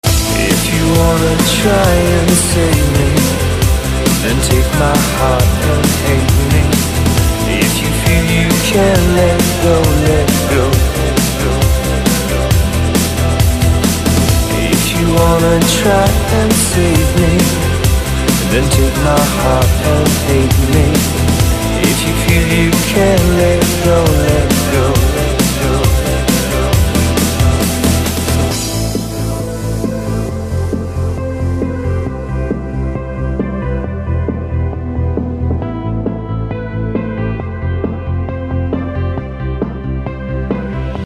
• Качество: 128, Stereo
красивый мужской голос
dance
электронная музыка
club
Trance
вокал